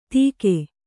♪ tīke